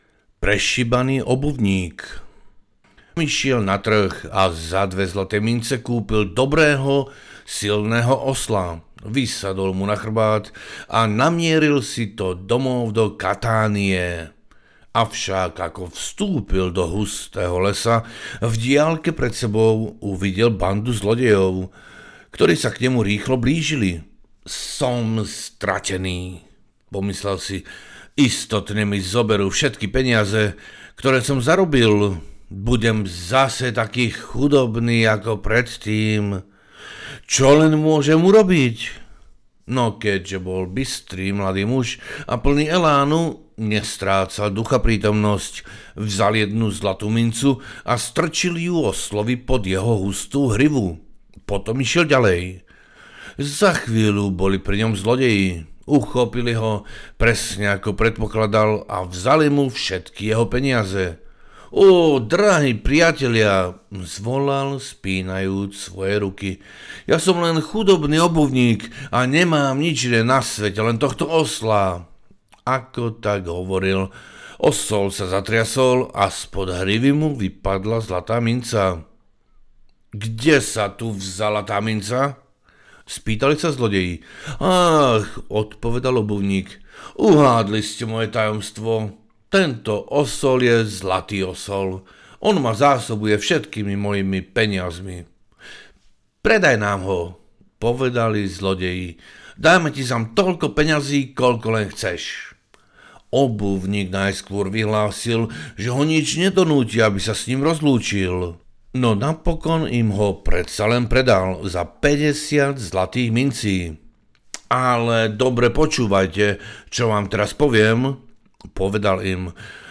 Ukázka z knihy
66-poviedok-na-vecery-a-noci-audiokniha